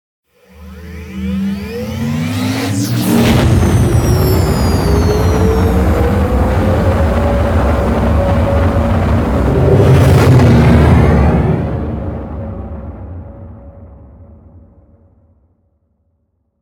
otherlaunch1.ogg